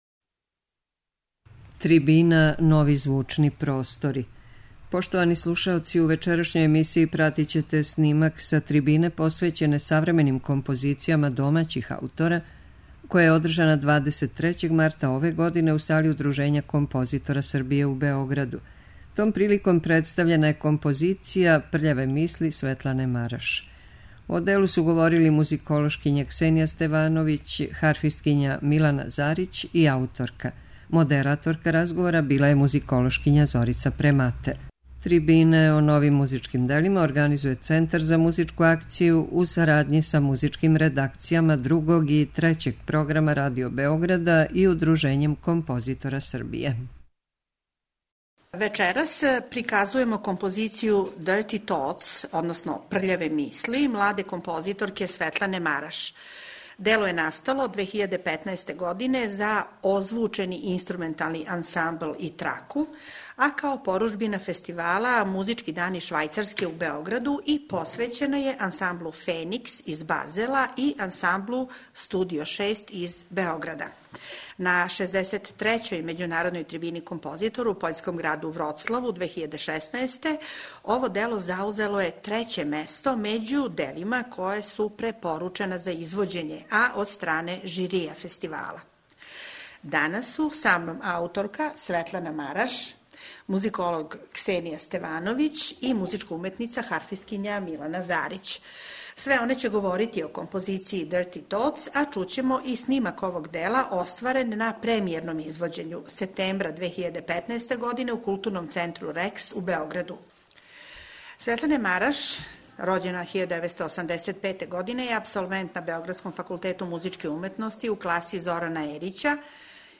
Трибина Нови звучни простори
Пратићете снимак са трибине посвећене савременим композицијама домаћих аутора, која је одржана 23. марта ове године у Сали Удружења композитора Србије у Београду.